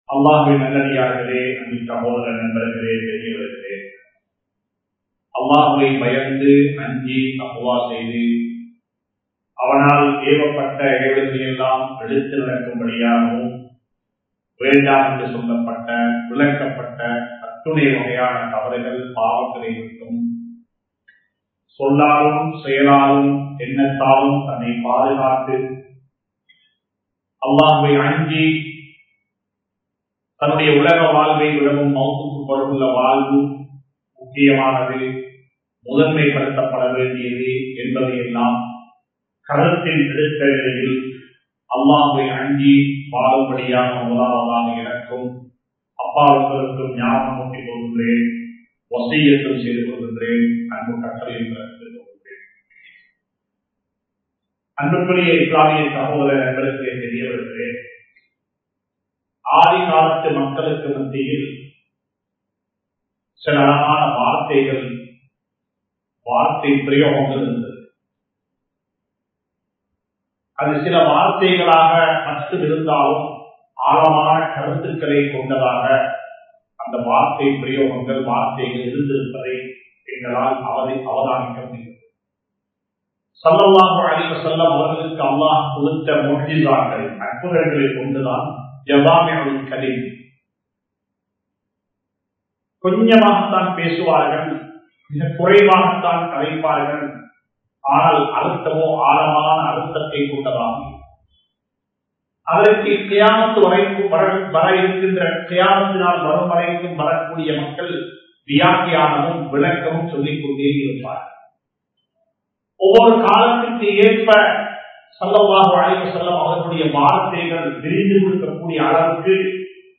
அயலவரை மதியுங்கள் (Respect the Neighbors) | Audio Bayans | All Ceylon Muslim Youth Community | Addalaichenai